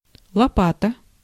Ääntäminen
US : IPA : ['ʃʌv.ᵊl]